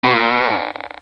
fart1.wav